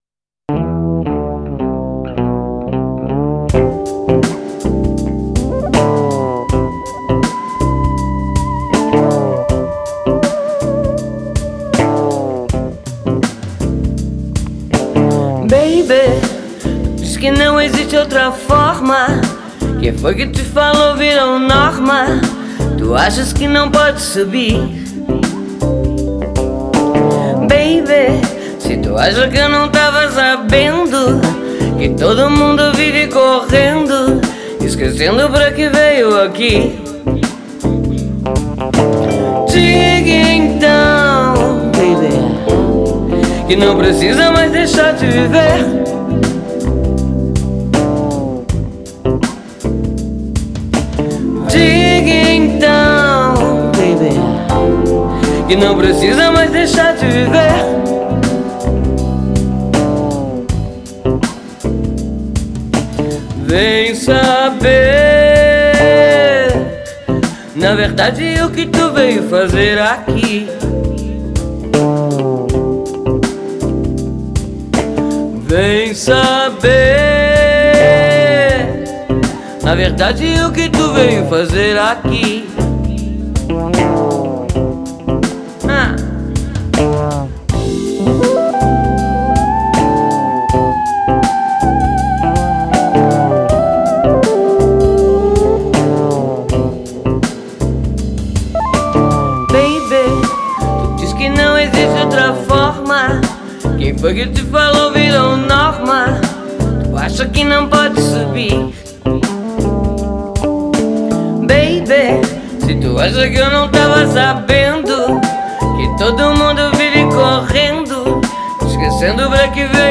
o rock...